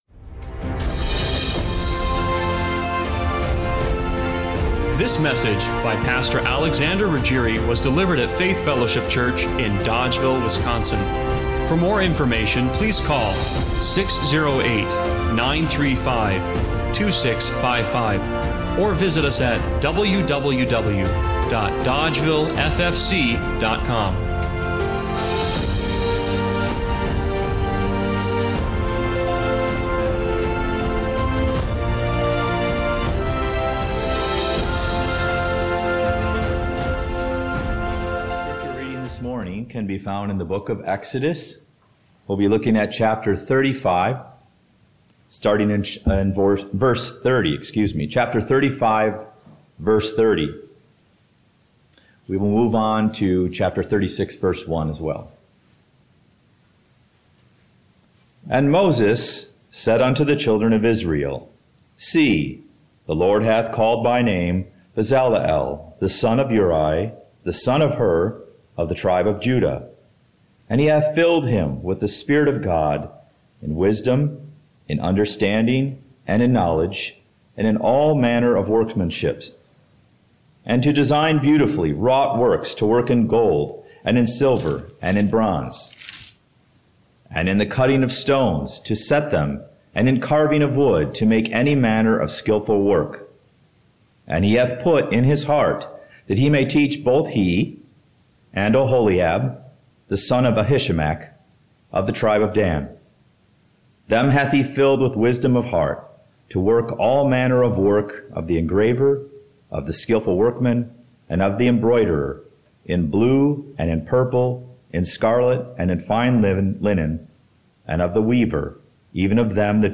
In this sermon, we see how God makes our minds holy ground—set apart and renewed for His purpose.
1 Thessalonians 5:23 Service Type: Sunday Morning Worship Is it possible that the greatest battles—and breakthroughs—happen in your mind?